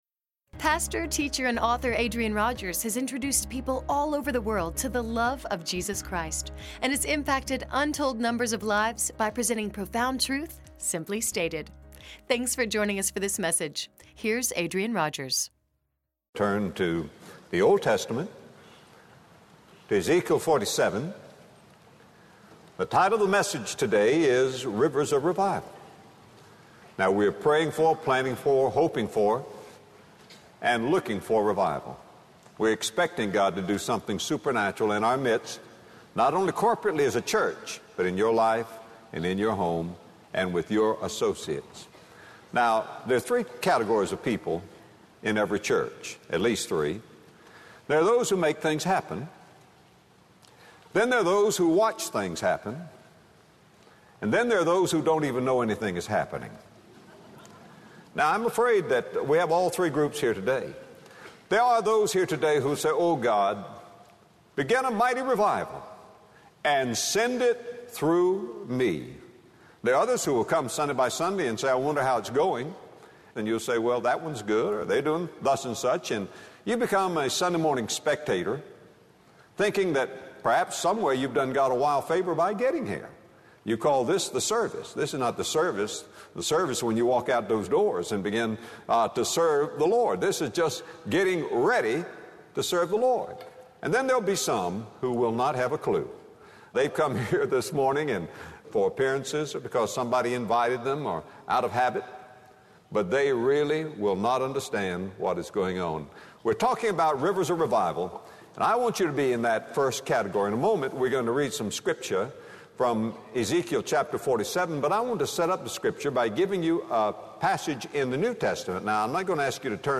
As we ask God to do something supernatural in our desperate world, we must pray for revival. In this message, Adrian Rogers reveals the source, course, and force of the rivers of revival pictured in Ezekiel 47.